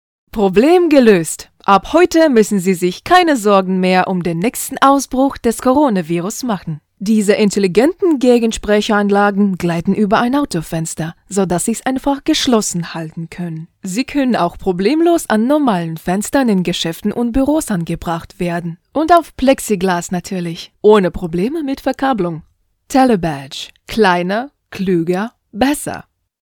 德语女4_外语_小语种_01iss.mp3